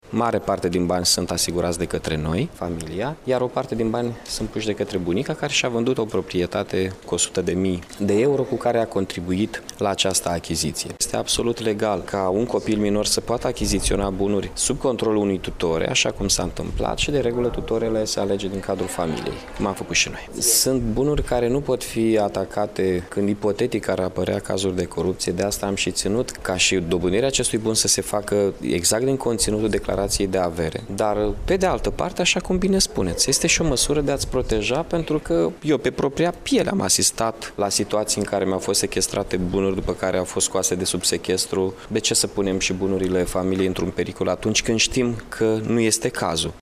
Vezi reacţia edilului